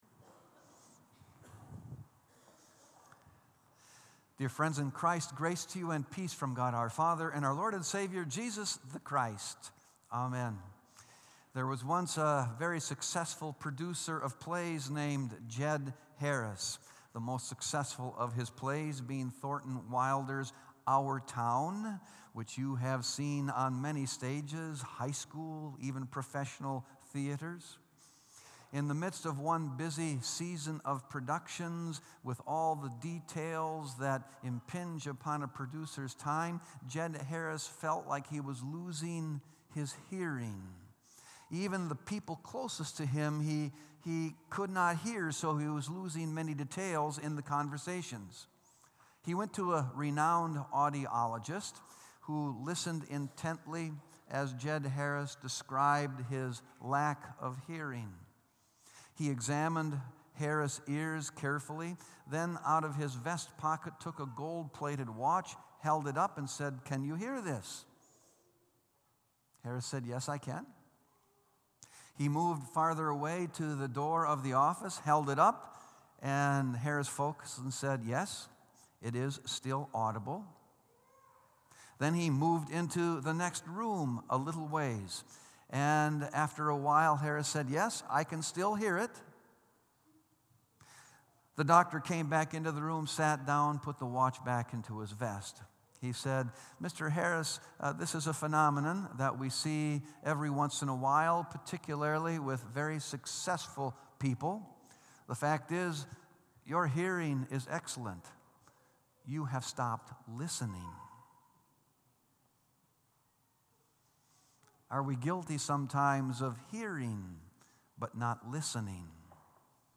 Sermon “Hearing is Not Listening”